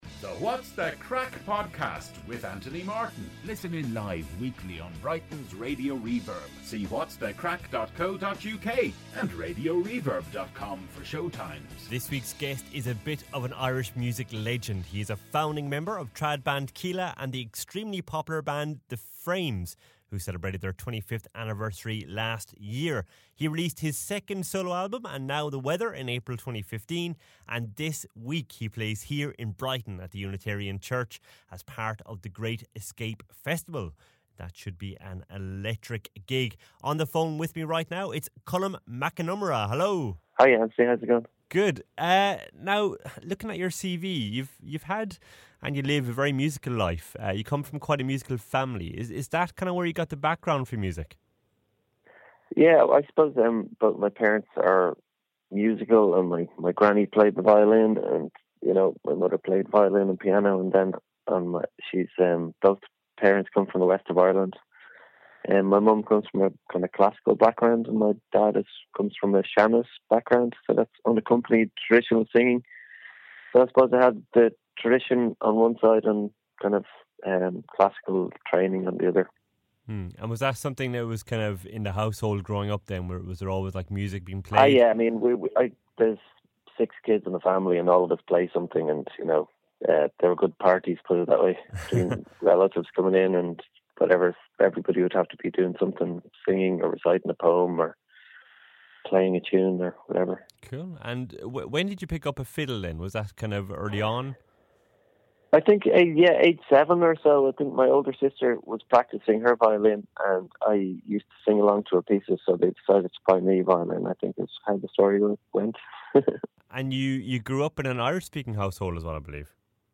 A chat with musician Colm Mac Con Iomaire